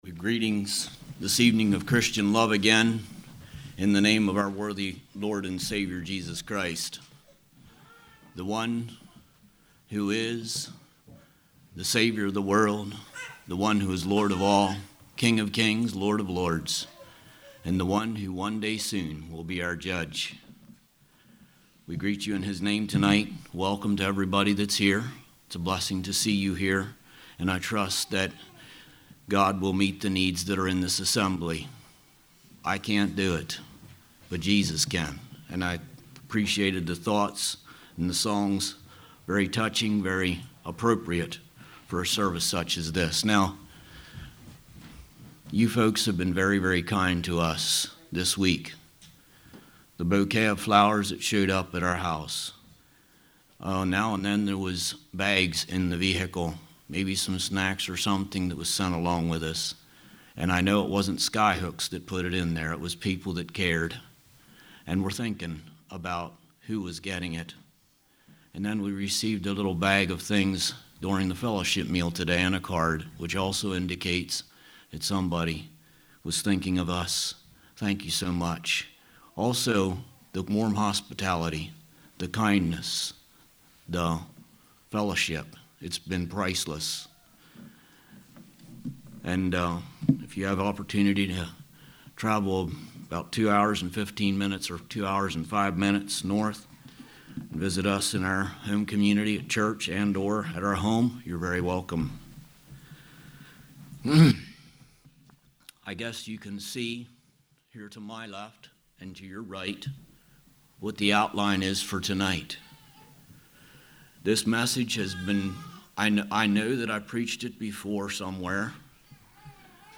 2020 Sermon ID